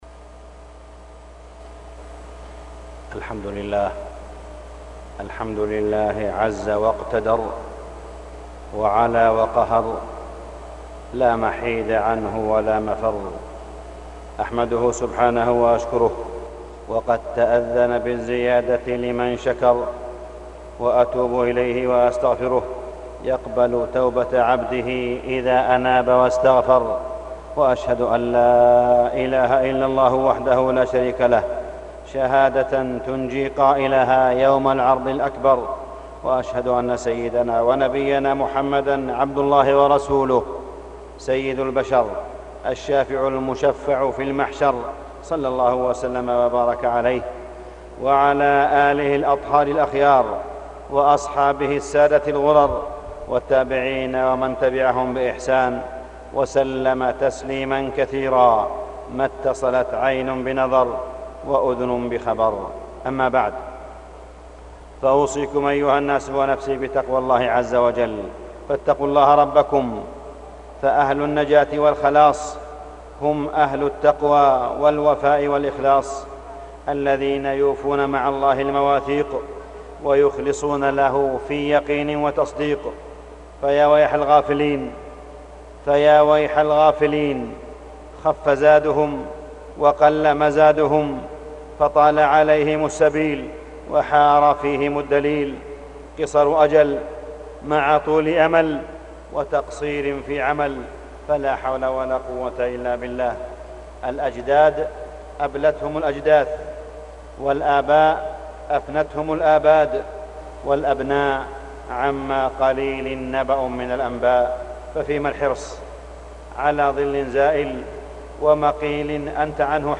تاريخ النشر ١٠ صفر ١٤٢٧ هـ المكان: المسجد الحرام الشيخ: معالي الشيخ أ.د. صالح بن عبدالله بن حميد معالي الشيخ أ.د. صالح بن عبدالله بن حميد المرأة بين ظلم الجاهلية والعدالة الإسلامية